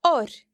When R is not the first letter in a word and occurs next to e or i, it is considered slender, and can be heard in oir (an edge):